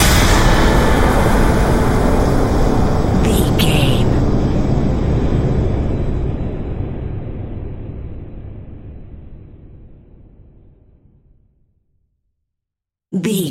Atonal
tension
ominous
dark
haunting
eerie
percussion